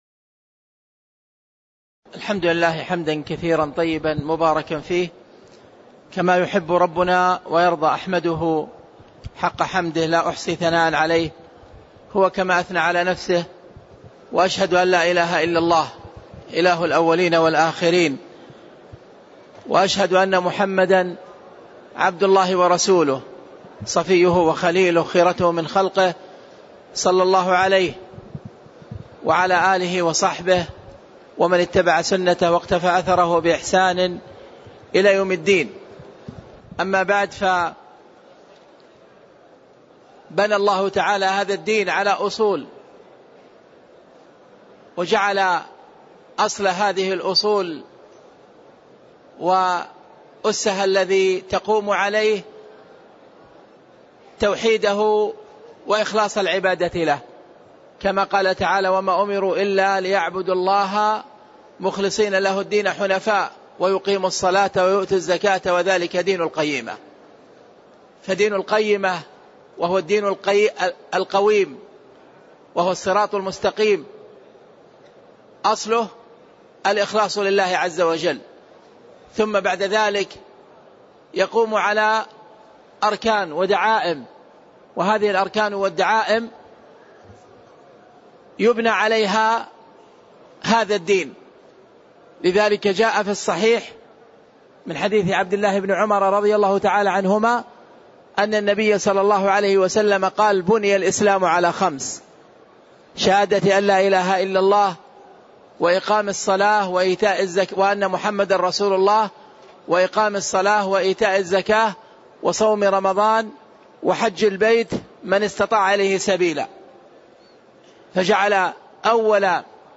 تاريخ النشر ٨ ذو القعدة ١٤٣٦ هـ المكان: المسجد النبوي الشيخ